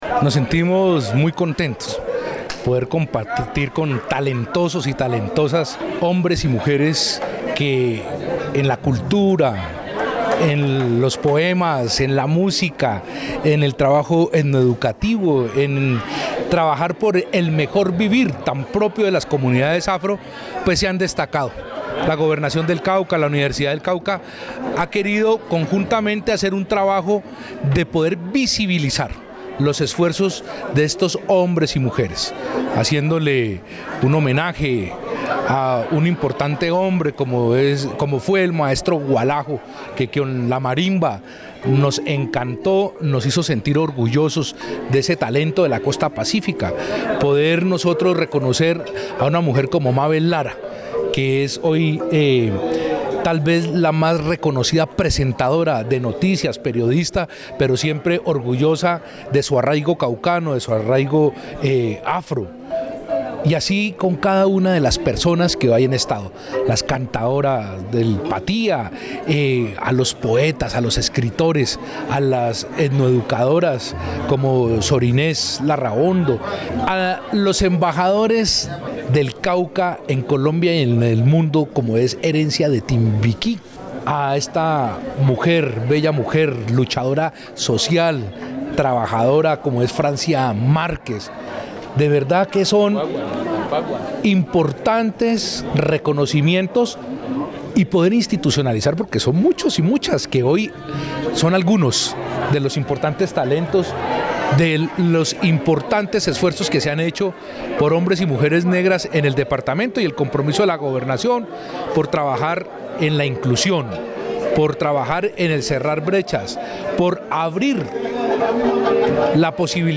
El evento liderado por la Gobernación del Cauca y apoyado por la Universidad del Cauca, se llevó a cabo en el Salón de los espejos del edificio departamental, donde los homenajeados recibieron por parte del Gobernador del Cauca, Óscar Rodrigo Campo Hurtado,  la condecoración  con la Orden Civil al Mérito.
FULL_OSCAR-RODRIGO-CAMPO-HURTADO_GOBENADOR-DEL-CAUCA.mp3